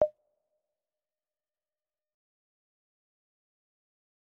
Timbaland will sometimes use the ‘Metronome’ effect as a percussive element, applying a slight reverb to give atmosphere to the sound.
Metronome FX
Metronome_FX_Example_1.mp3